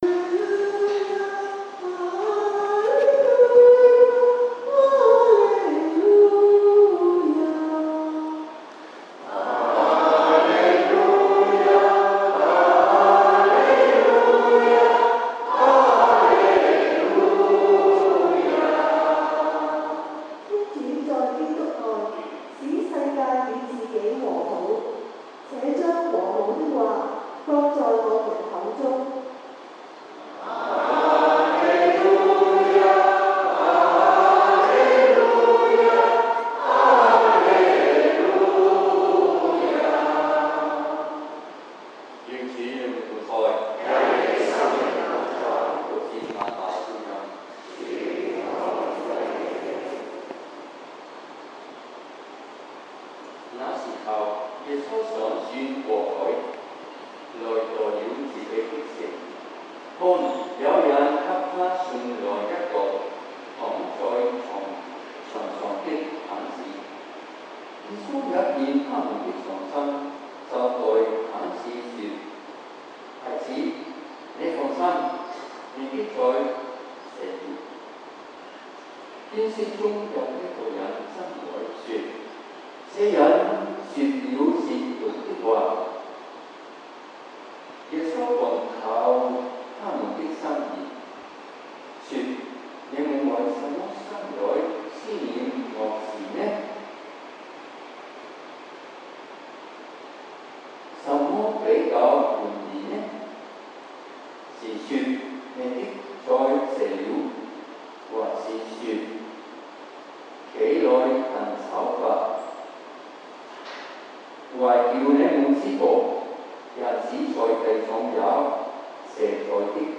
7:15am, 英文講道